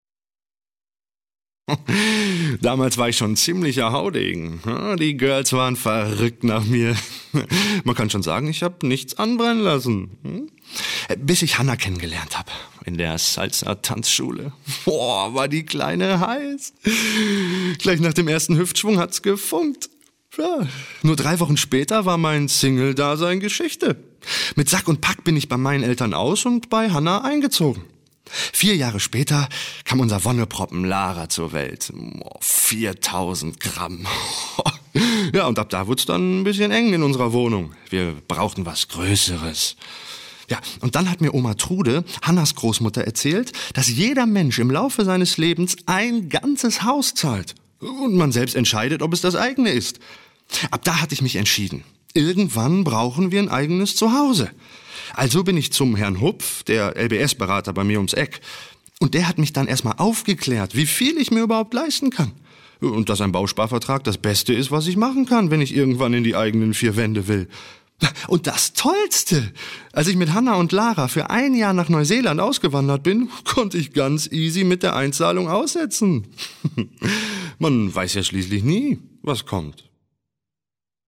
Frische, dynamische, flexible und markante Sprech- und Gesangsstimme, optimal für Werbung, Voice Over, Synchron, Dokumentationen, Hörbücher und Hörspiele.
Sprechprobe: Sonstiges (Muttersprache):
My voice is fresh, dynamic, warm, flexible and striking.